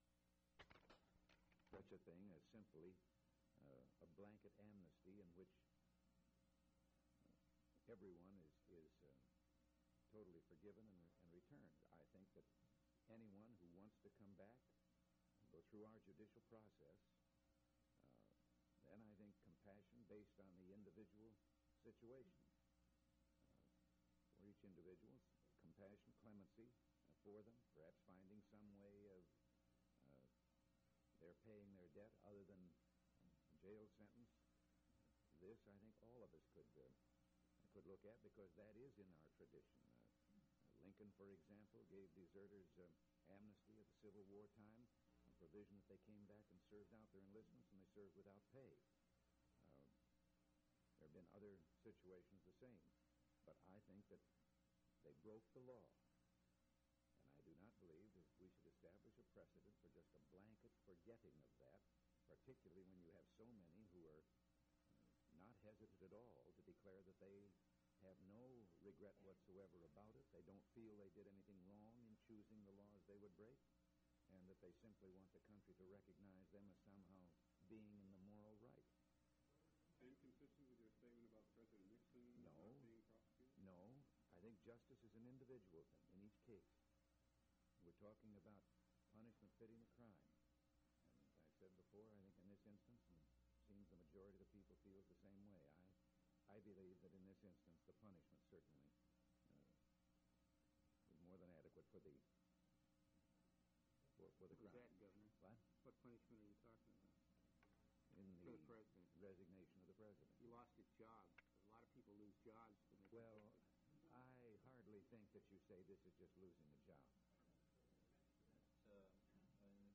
Governor Ronald Reagan News Conference
Audio Cassette Format.